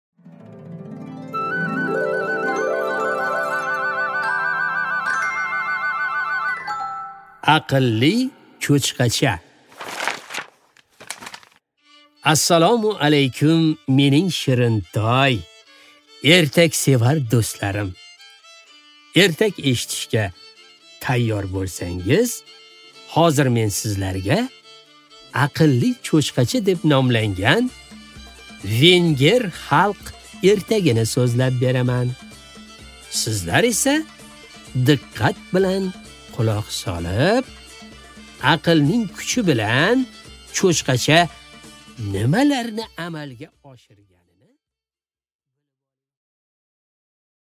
Аудиокнига Aqlli cho'chqacha | Библиотека аудиокниг